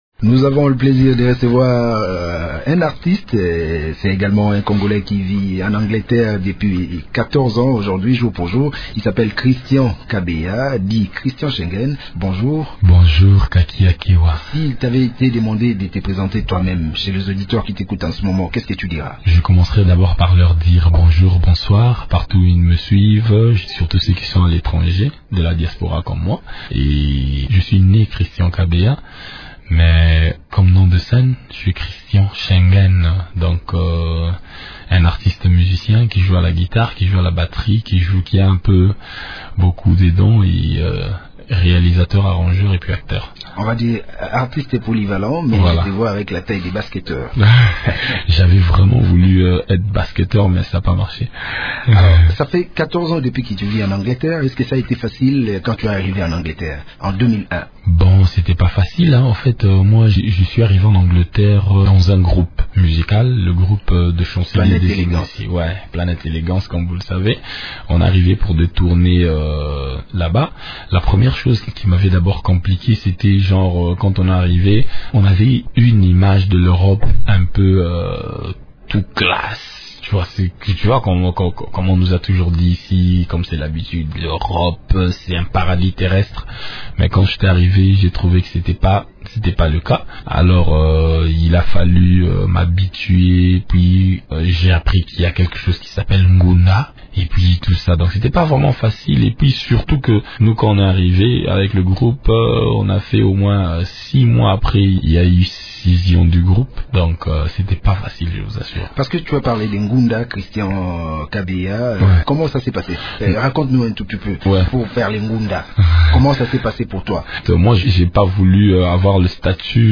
dans le studio de Radio Okapi.